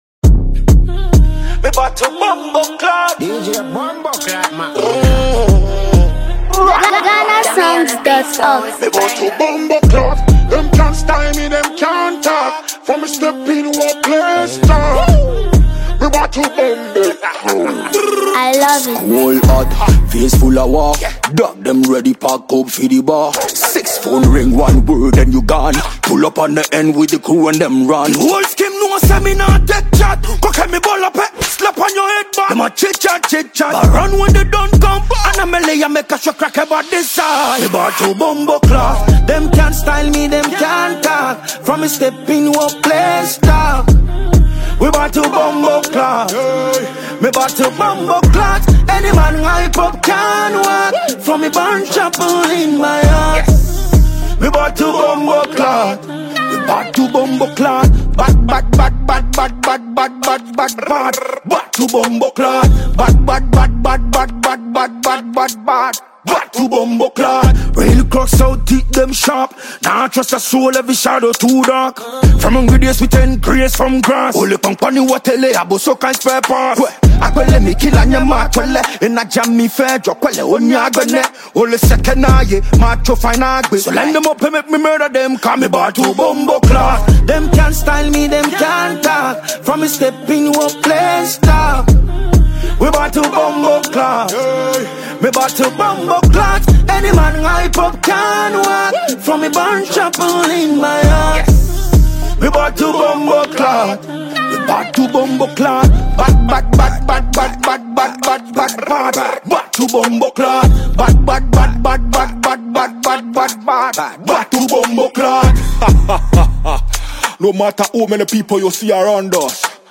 Ghanaian dancehall